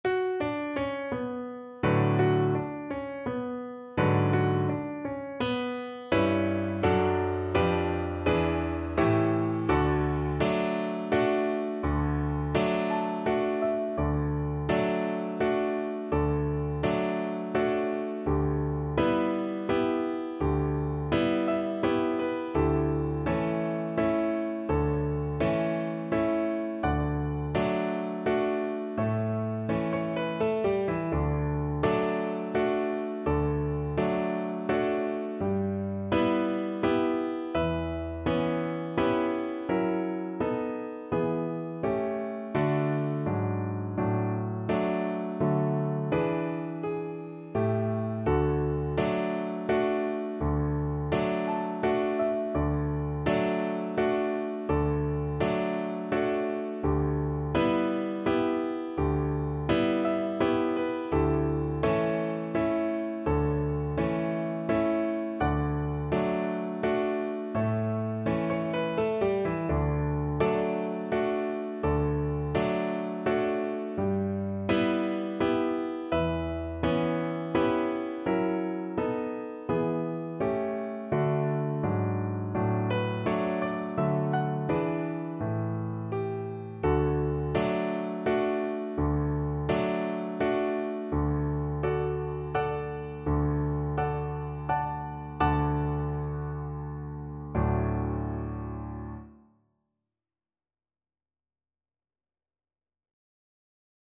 3/4 (View more 3/4 Music)
= 84 Andante non troppe e molto maestoso